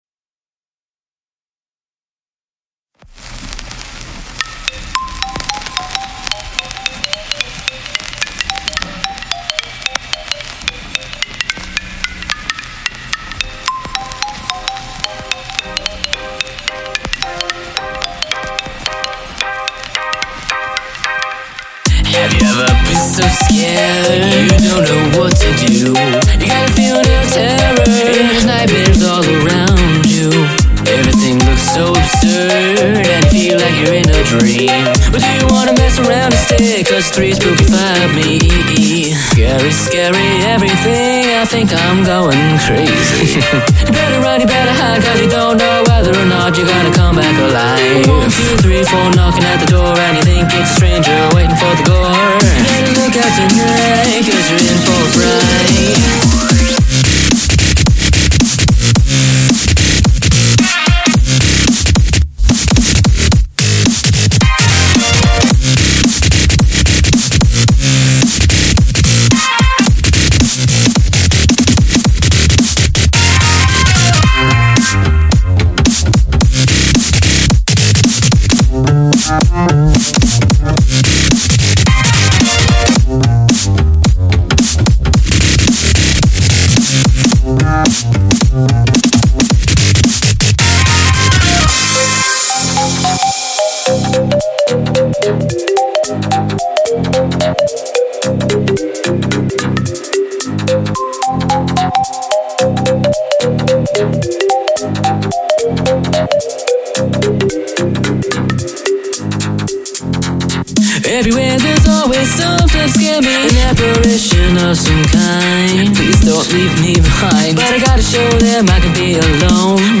----------------------------- Tempo: 100 Key: C Produced by